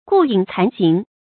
顾影惭形 gù yǐng cán xíng
顾影惭形发音